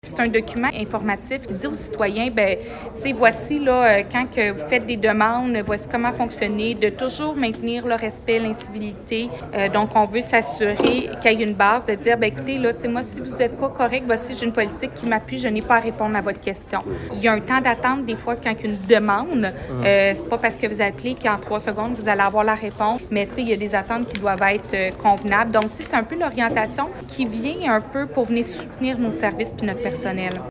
Nouvelles
La mairesse de Granby, Julie Bourdon :